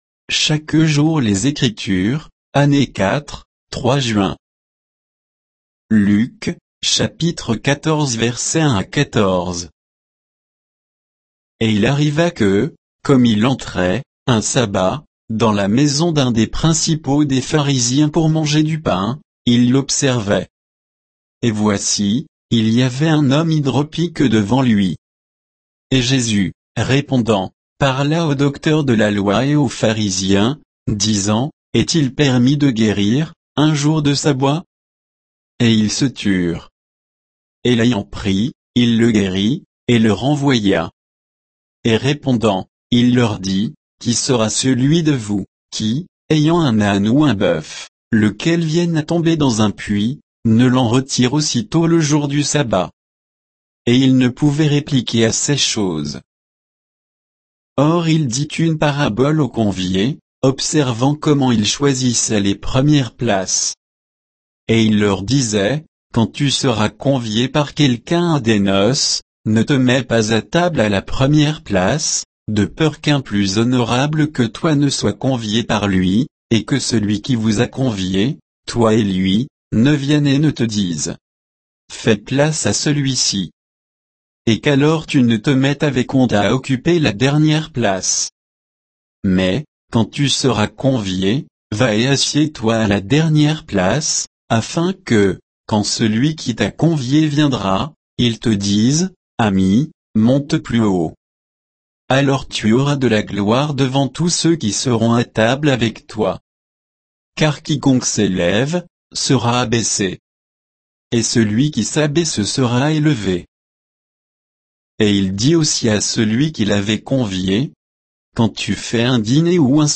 Méditation quoditienne de Chaque jour les Écritures sur Luc 14, 1 à 14